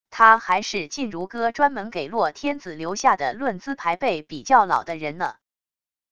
他还是靳如歌专门给洛天子留下的论资排辈比较老的人呢wav音频生成系统WAV Audio Player